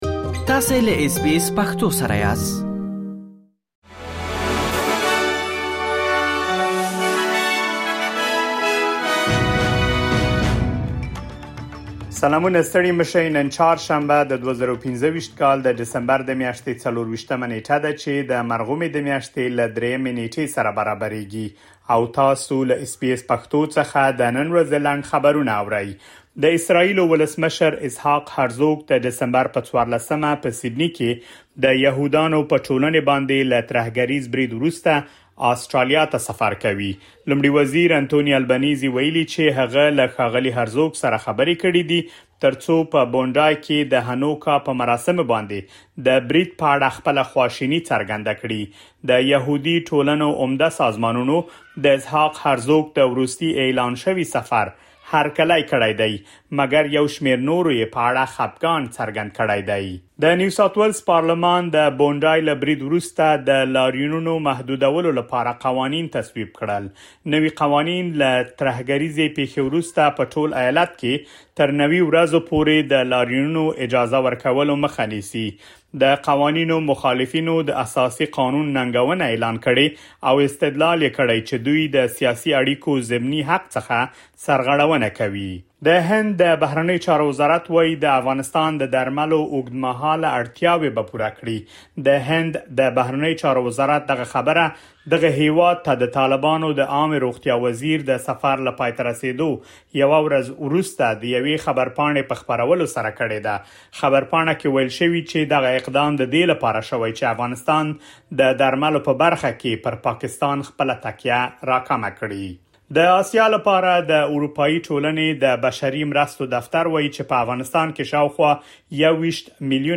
د اس بي اس پښتو د نن ورځې لنډ خبرونه |۲۴ ډسمبر ۲۰۲۵
د اس بي اس پښتو د نن ورځې لنډ خبرونه دلته واورئ.